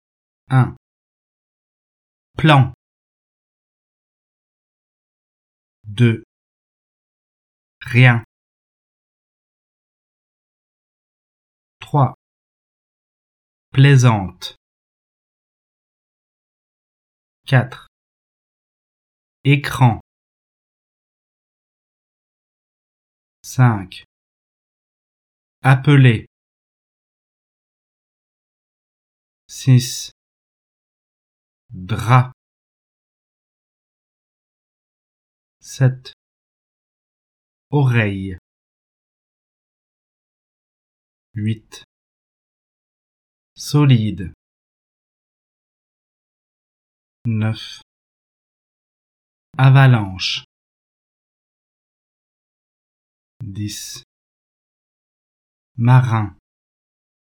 Entendez-vous le son [ ʁ ] ou [ l ] ?